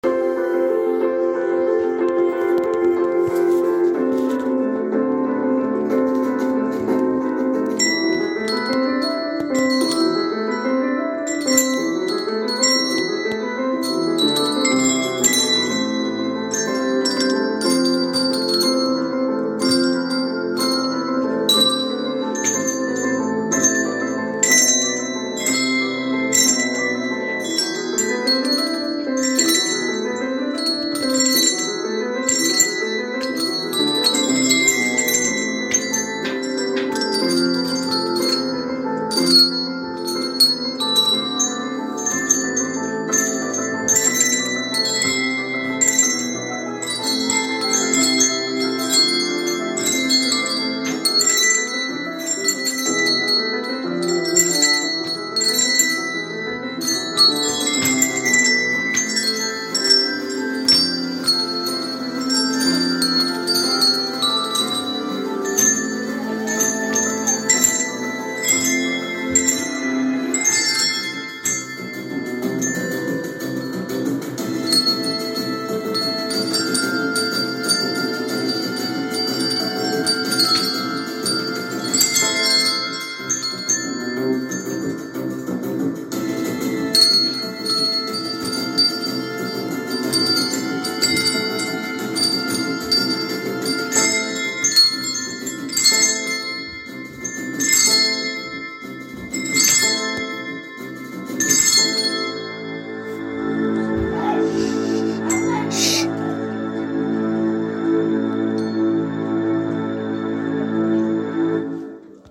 And also, a couple of pieces of music performed by Year 4 on bells.